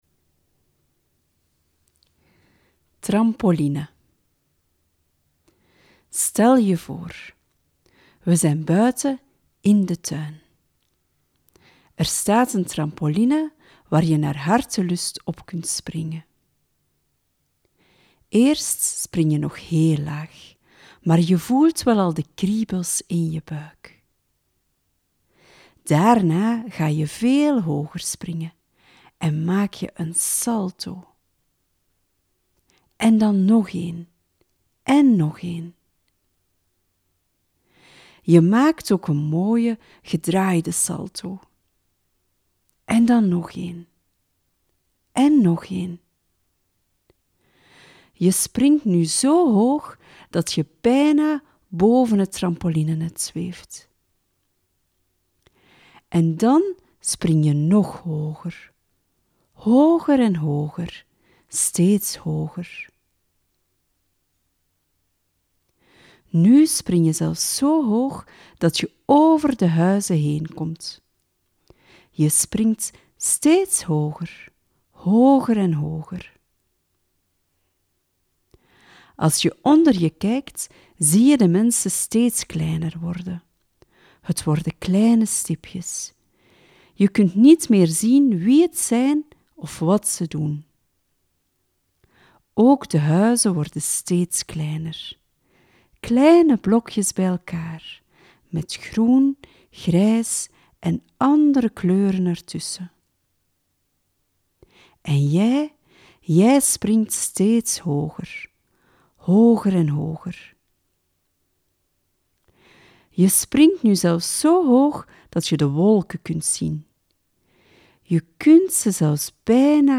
Een begeleide meditatie- of visualisatie-oefening kan je hierbij helpen.